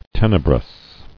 [ten·e·brous]